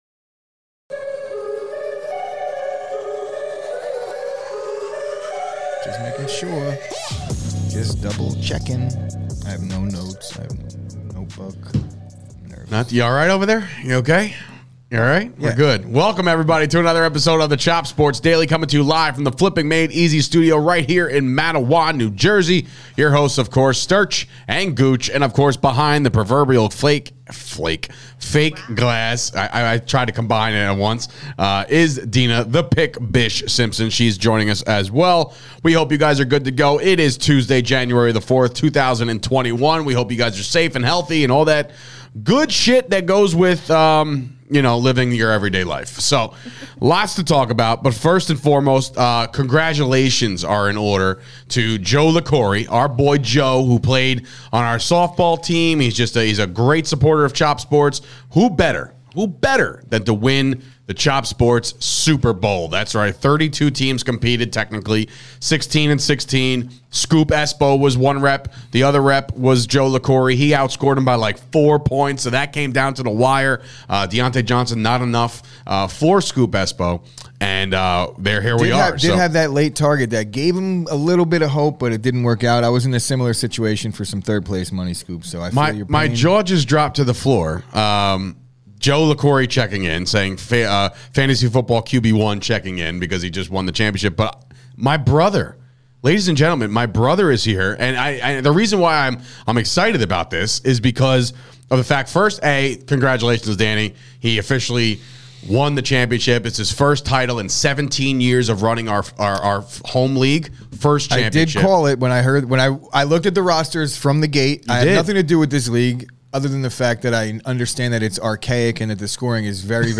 LIVE from the Flipping Made EZ Studio in Matawan, NJ! The Pittsburgh Steelers keep themselves mathematically alive in the AFC Playoff Picture with a big win over the Cleveland Browns.